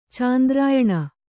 ♪ cāndrāyaṇa